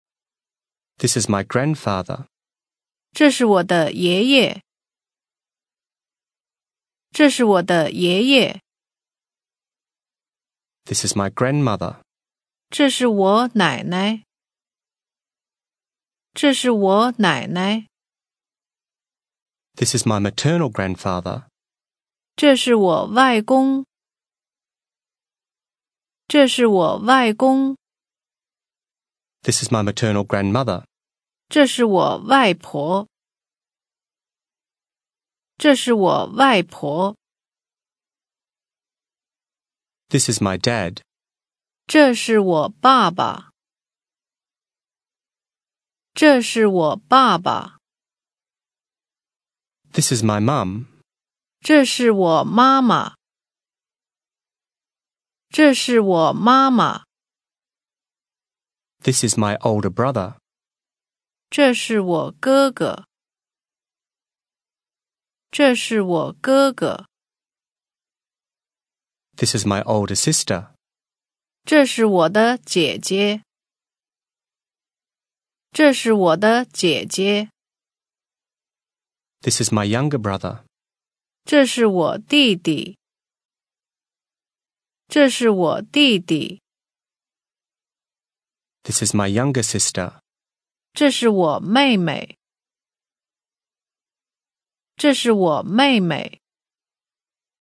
Listen and repeat - This is my